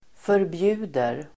Uttal: [förbj'u:der]